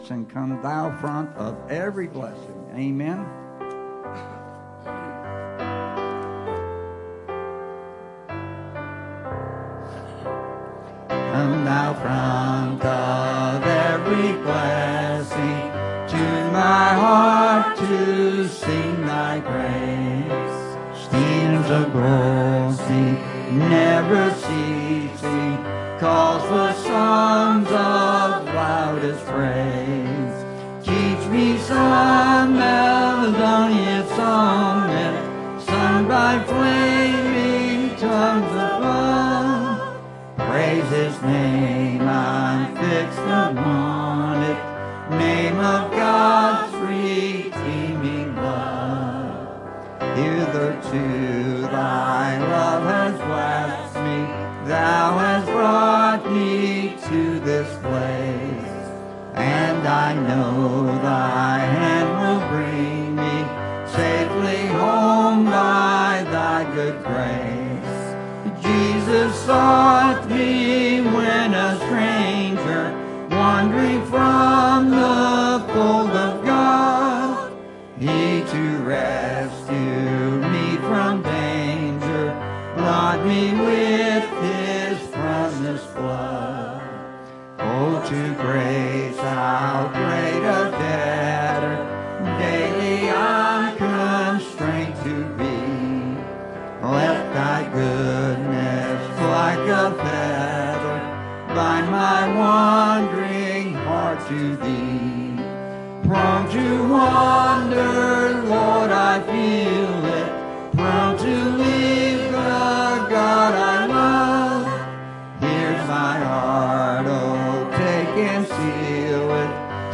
Bible Text: Psalm 91:1-16 | Preacher